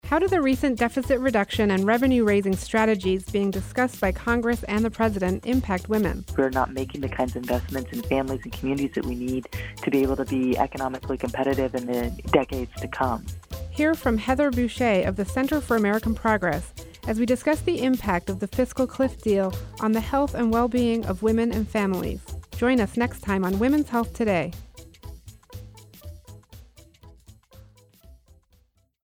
Women’s Health Today promo